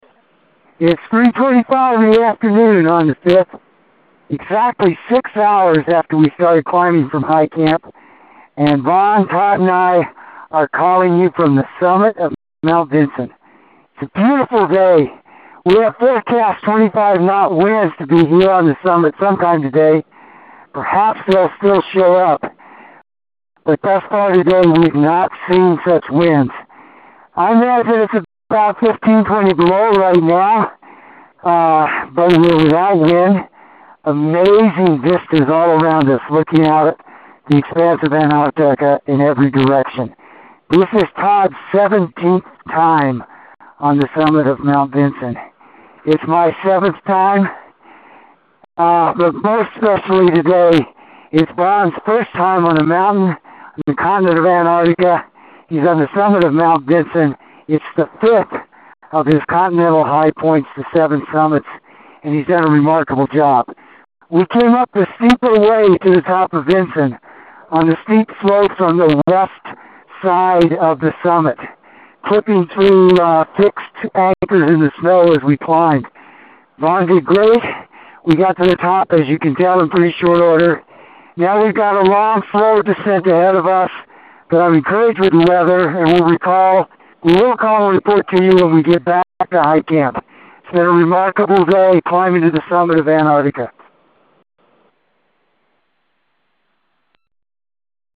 Made it to the Summit of Mt. Vinson!